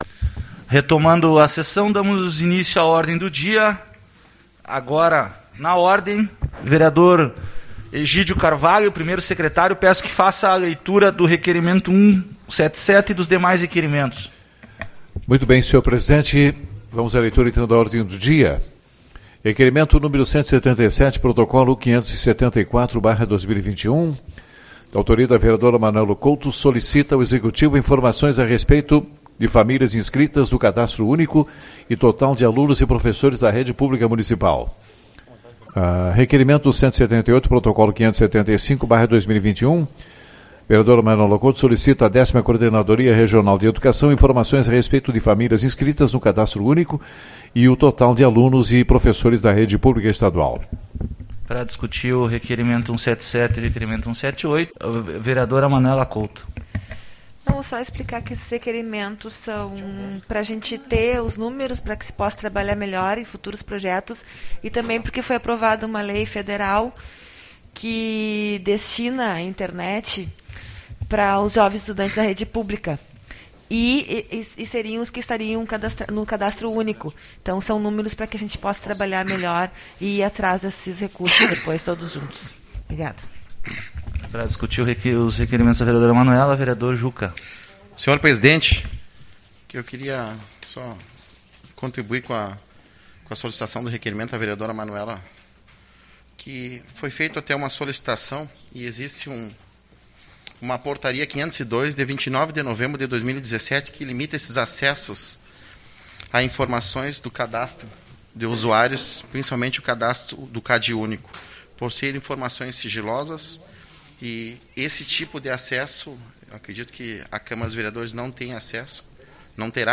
08/06 - Reunião Ordinária